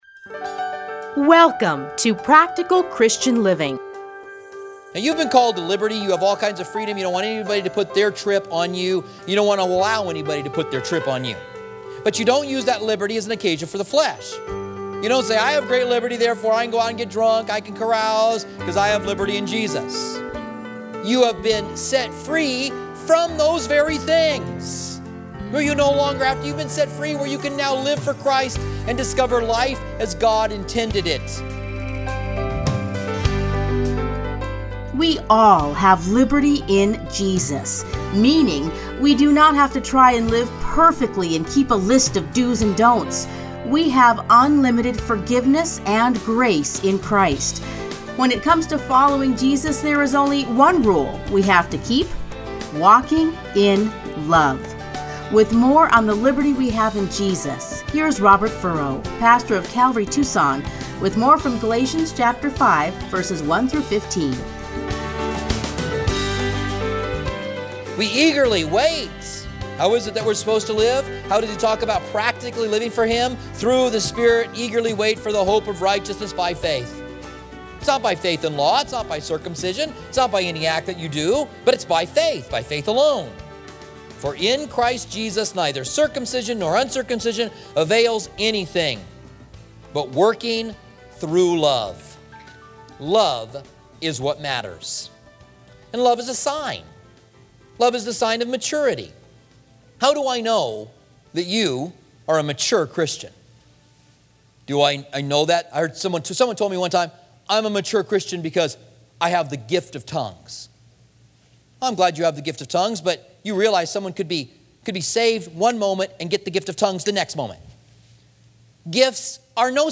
Playlists Commentary on Galatians Download Audio